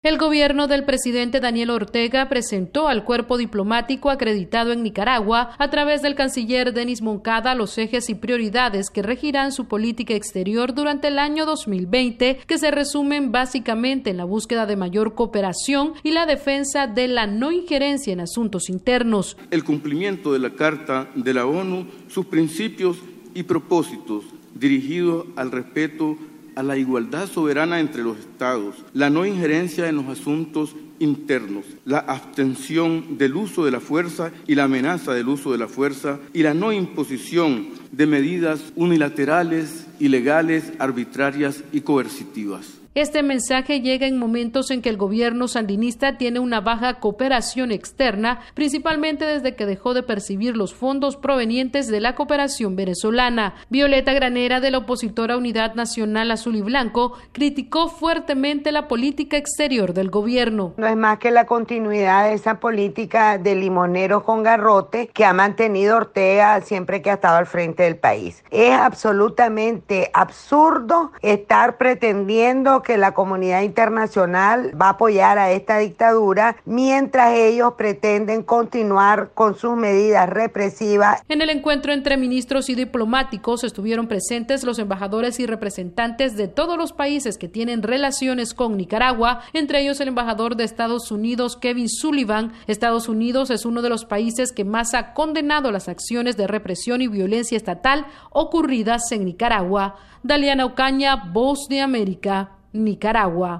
VOA: Informe de Nicaragua